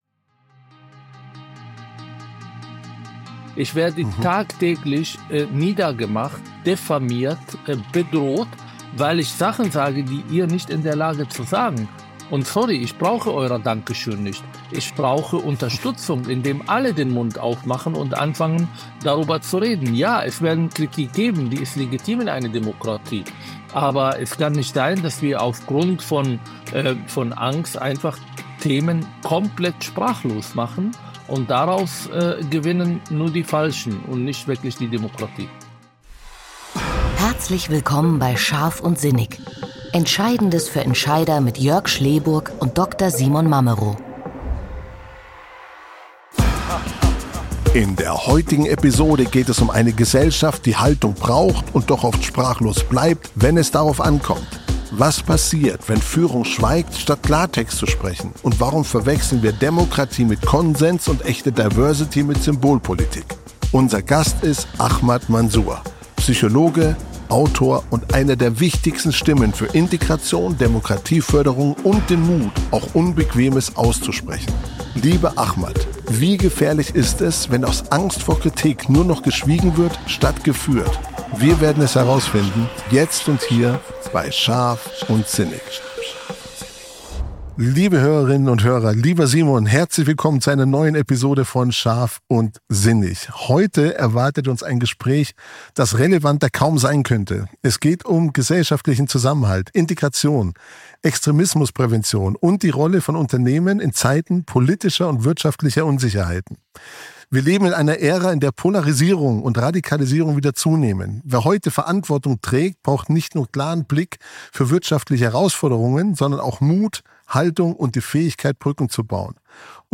Ahmad Mansour (Gast)